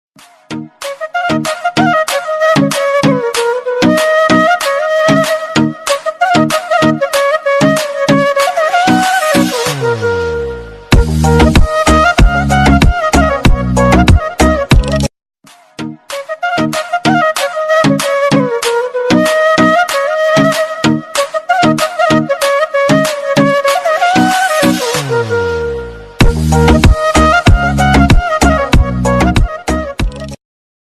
Category: Instrumental Ringtones